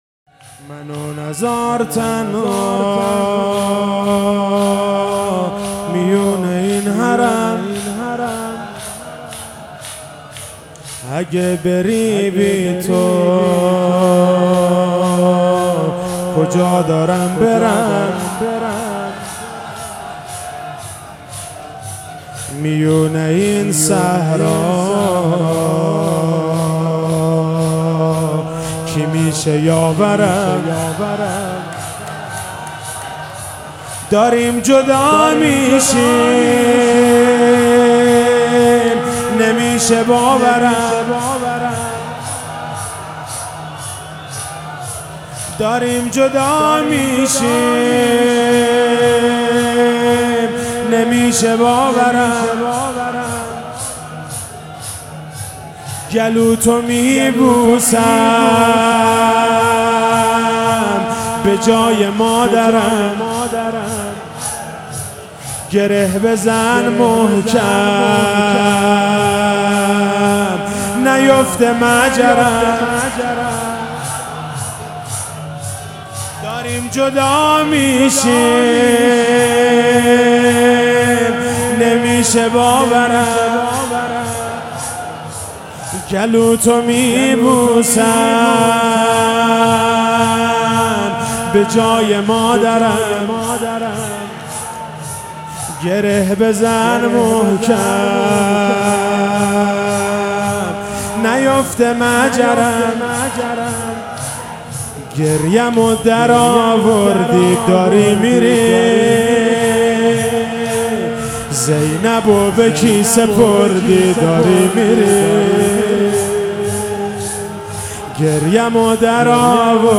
شب زیارتی امام رضا (ع)97 - شور - منو نزار تنها میون این حرم